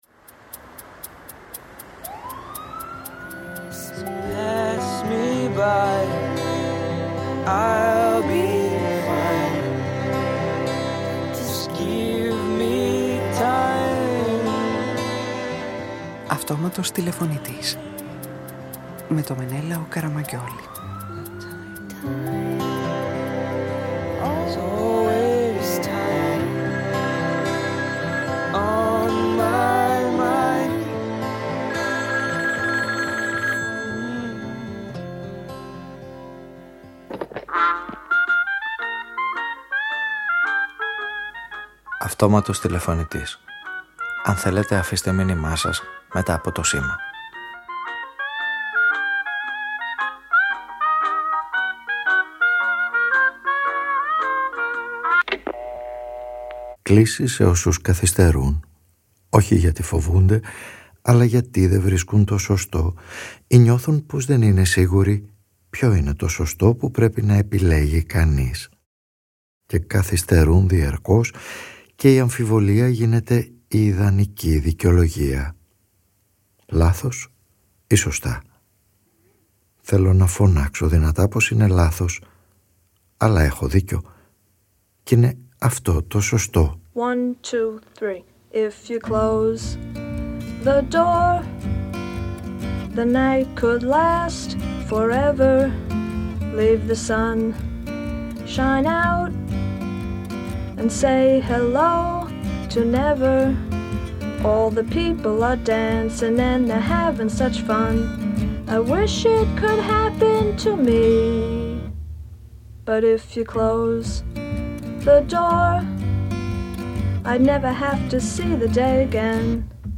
Μια 20χρονη ιδιοφυΐα στα μαθηματικά ζητάει να νοσηλευτεί με τη θέλησή της κι οι συνεδρίες της με τον ψυχίατρο της κλινικής γίνονται ο οδηγός σε μια ραδιοφωνική ταινία που οι ήρωές της (αυτοί που αναβάλλουν) ανακαλύπτουν μαζί της το πείραμα της διπλής σχισμής: μπορεί κανείς να περάσει μέσα από δύο ανοίγματα ταυτόχρονα;